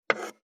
558肉切りナイフ,まな板の上,
効果音